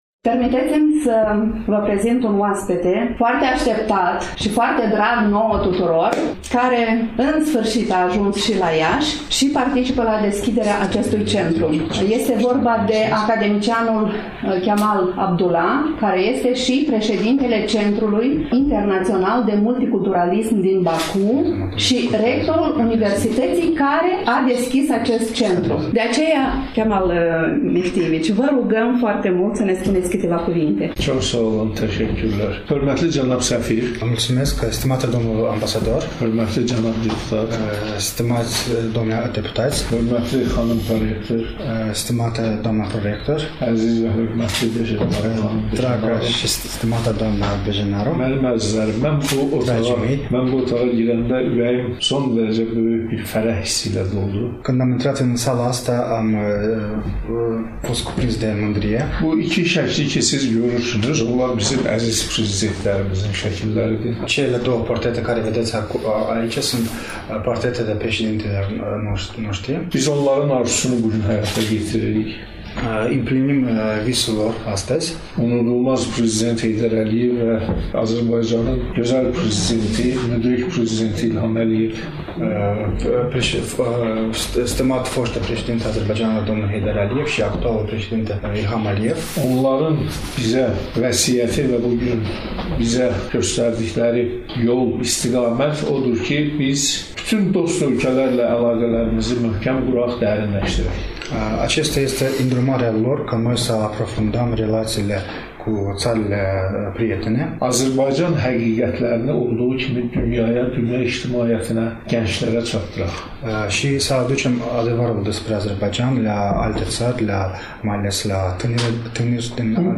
Dragi prieteni, de la același eveniment, în următoarele minute, îl ascultăm pe domnul academician Kamal Mehdi Abdullayev, director al Centrului Internațional de Multiculturalism din Baku, rector al Universității de Limbi din Baku, scriitor,